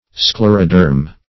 Search Result for " scleroderm" : The Collaborative International Dictionary of English v.0.48: Scleroderm \Scler"o*derm\ (? or ?; 277), n. [Gr. sklhro`s hard + de`rma skin: cf. F. scl['e]roderme.]